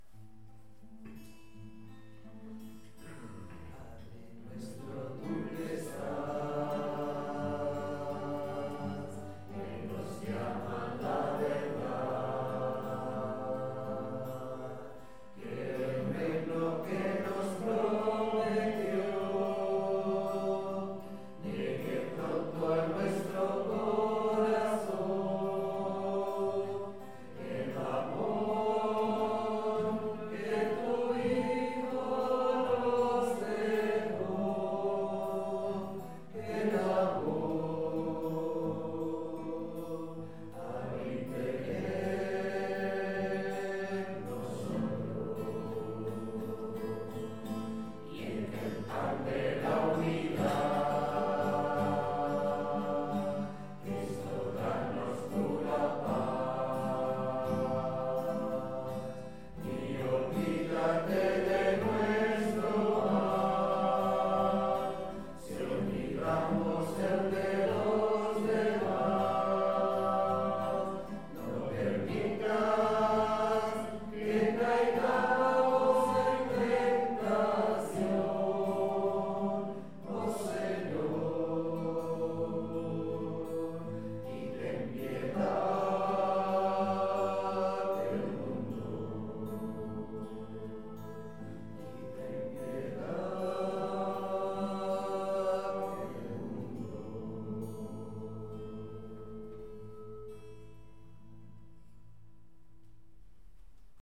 Pregària de Taizé a Mataró... des de febrer de 2001
Parròquia Maria Auxiliadora - Diumenge 26 de març de 2023
Vàrem cantar...